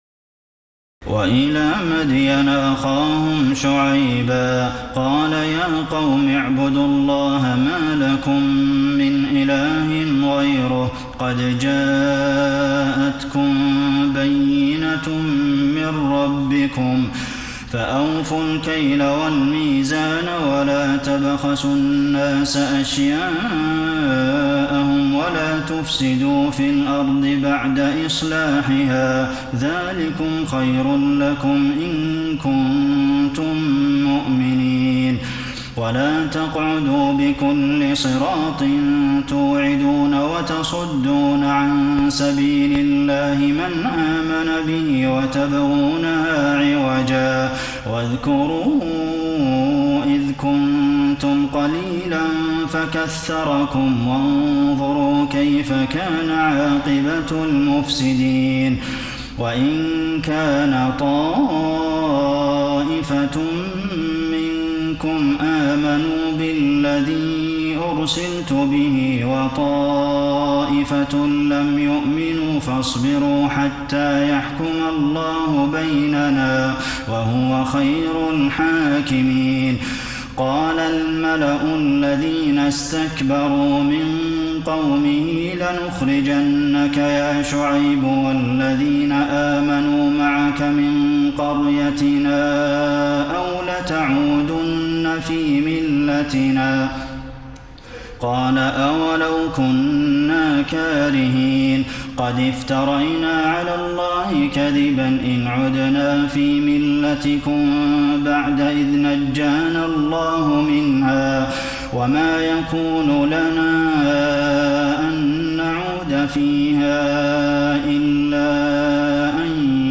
تراويح الليلة التاسعة رمضان 1433هـ من سورة الأعراف (85-170) Taraweeh 9 st night Ramadan 1433H from Surah Al-A’raf > تراويح الحرم النبوي عام 1433 🕌 > التراويح - تلاوات الحرمين